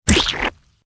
CHQ_SOS_pies_restock.ogg